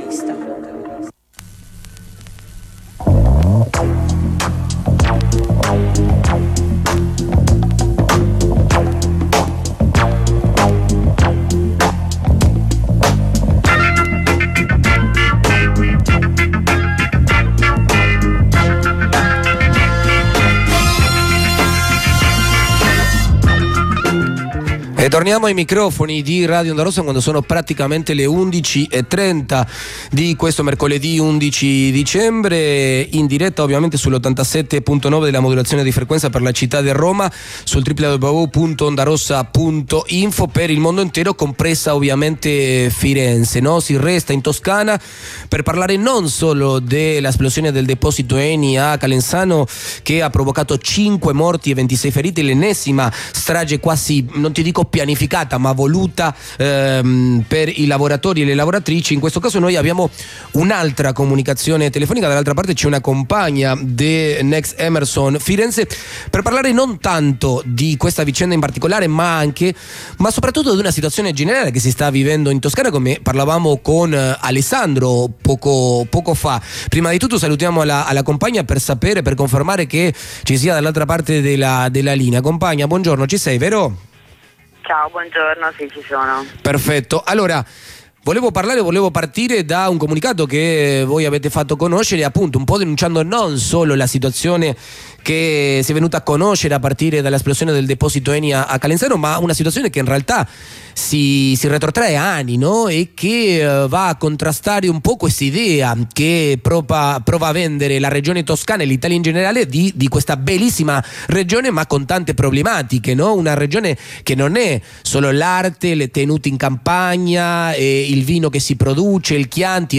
Comunicazione telefonica